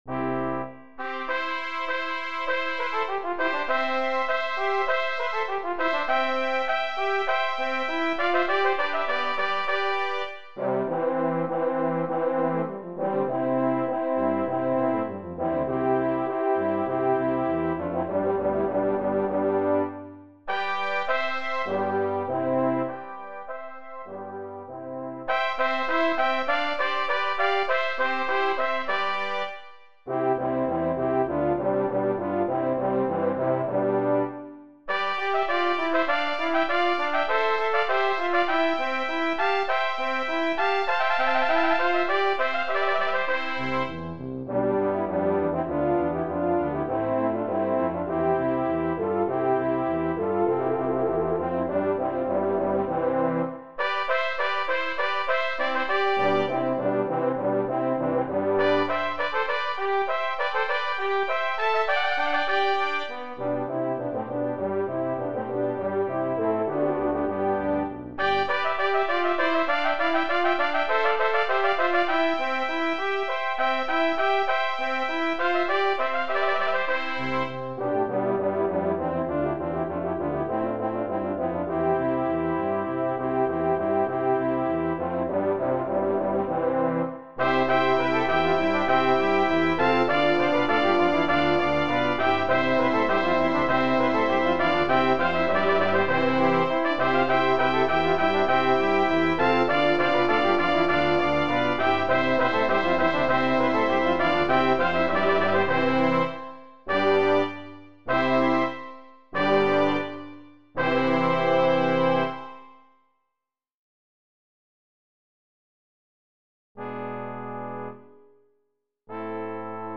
Voicing: 10 Brass